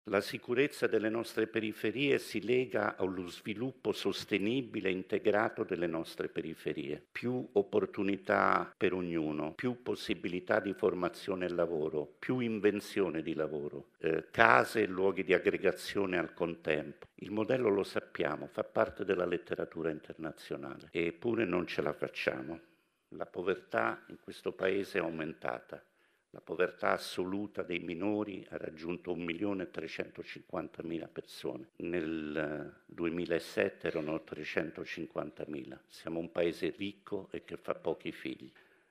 Ascoltiamo il presidente di Con i Bambini Marco Rossi Doria